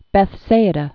(bĕth-sāĭ-də)